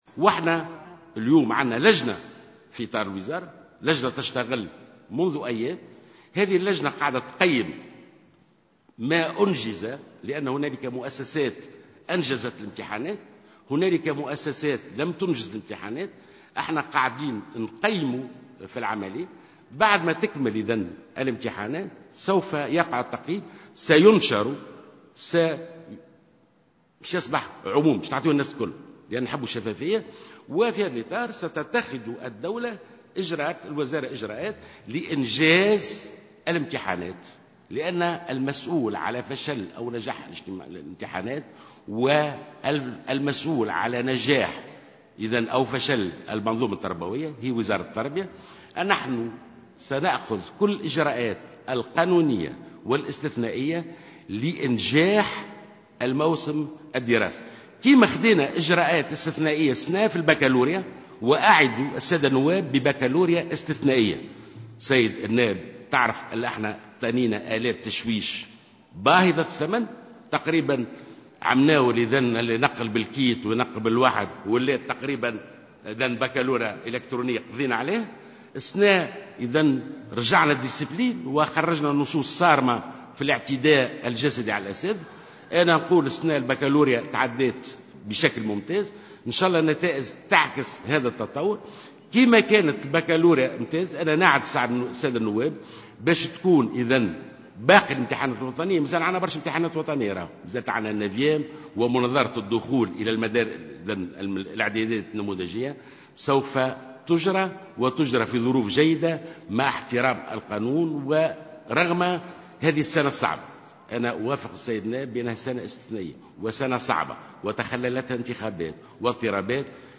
ووعد الوزير خلال جلسة مساءلته بمجلس نواب الشعب "ببكالوريا استثنائية" هذا العام،مشيرا إلى أن بقية الامتحانات الوطنية ستجرى في ظروف طيبة.